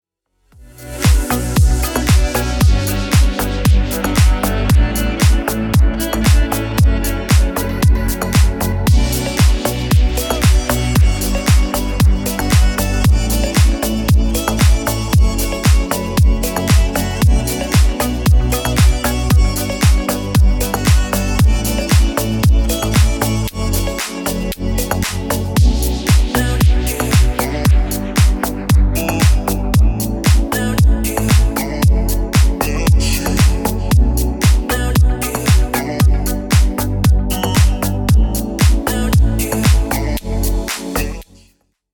• Качество: 320, Stereo
мужской голос
remix
Electronic
EDM
клубняк
Стиль: deep house